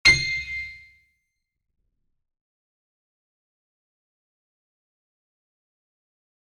HardAndToughPiano
c6.mp3